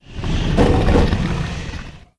c_hydra_atk3.wav